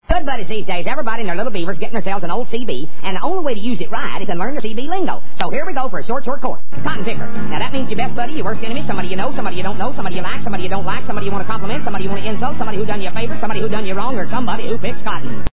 CB Lingo Sound Effects
The sound bytes heard on this page have quirks and are low quality.